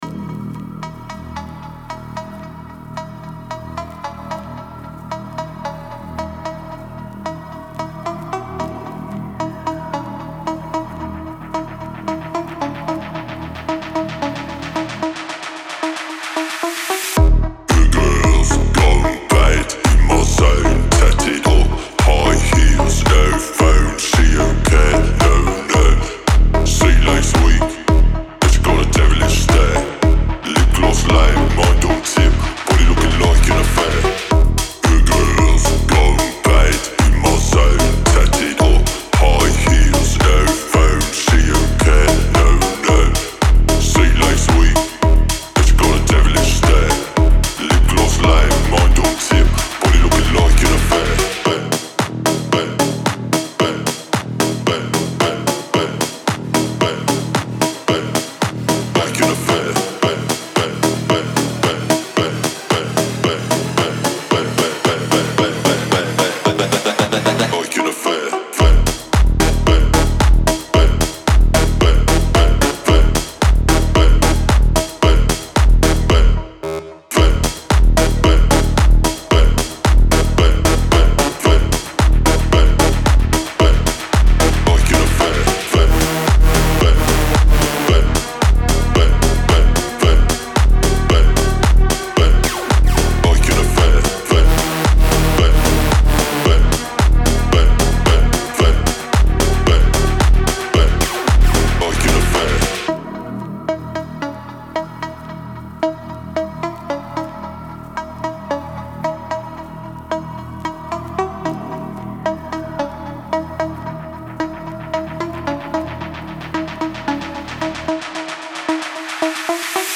Трек размещён в разделе Зарубежная музыка / Танцевальная.